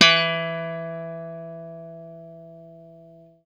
FENDRPLUCKAF.wav